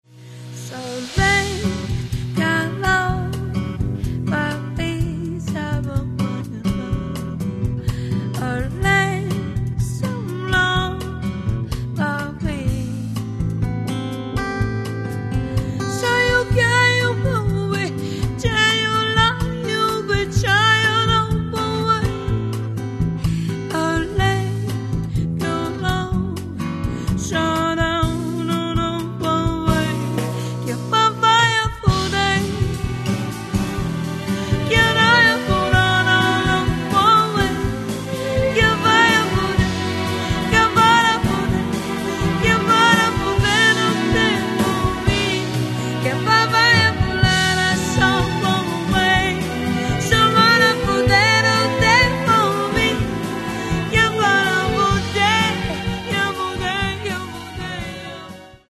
Каталог -> Джаз и около -> В Украине